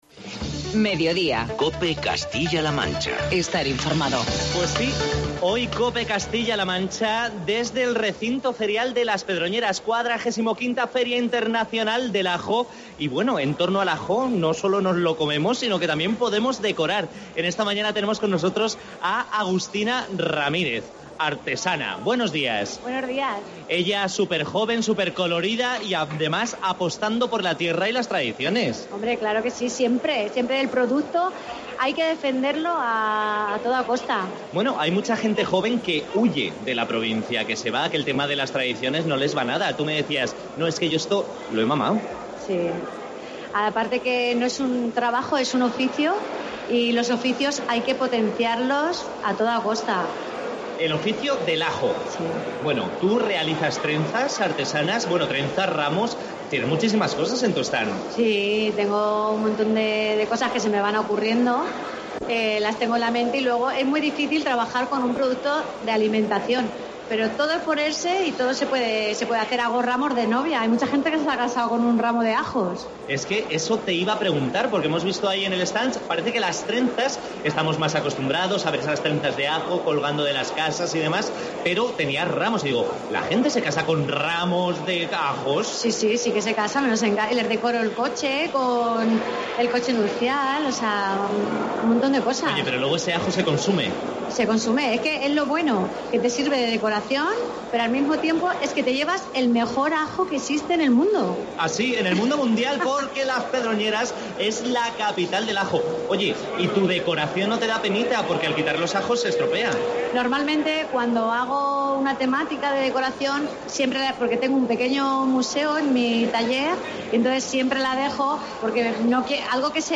COPE Castilla-La Mancha se ha trasladado a la XLV edición de la Feria Internacional del Ajo de las Pedroñeras para acercar a todos los castellanos manchegos las propiedades de este producto tan nuestro, desde la denominada "Capital Mundial del Ajo", desde las Pedroñeras (Cuenca).